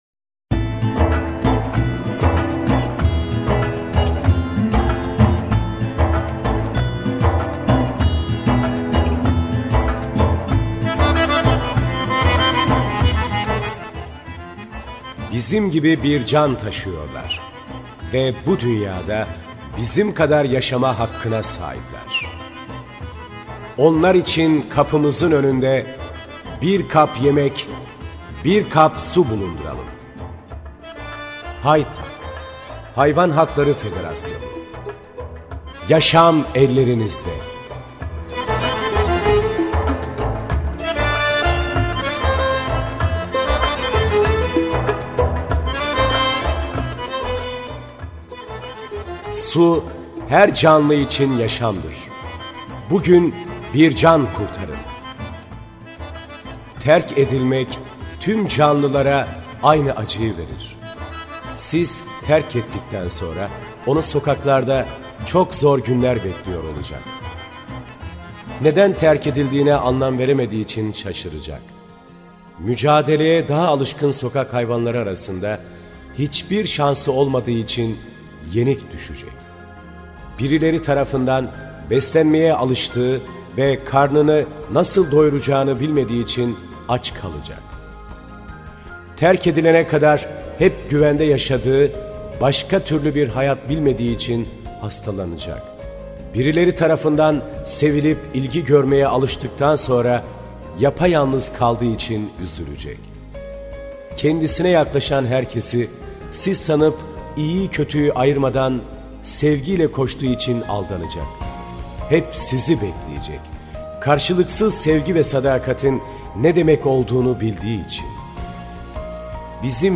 HAYTAP Anons DINI MUZIK Erkek Sesi ile